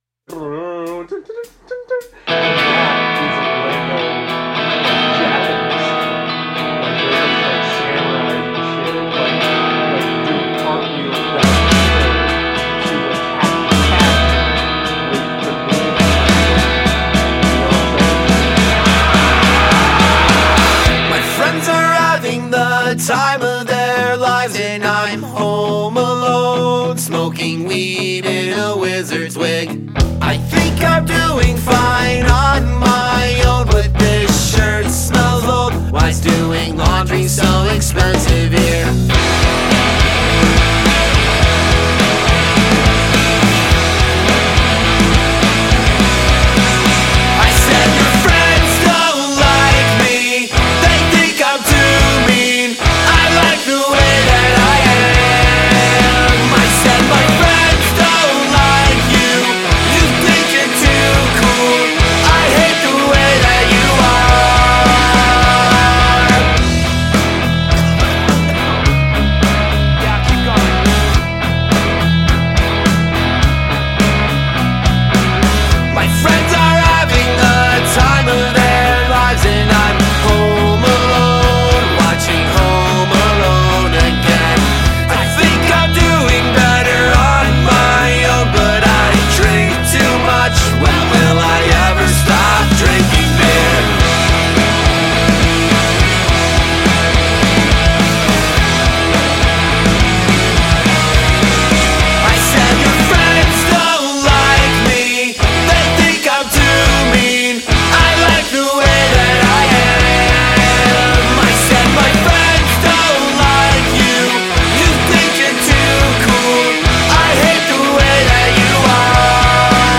punk rock band